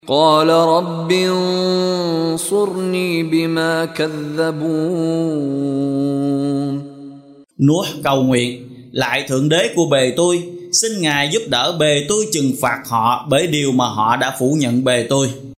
Đọc ý nghĩa nội dung chương Al-Muminun bằng tiếng Việt có đính kèm giọng xướng đọc Qur’an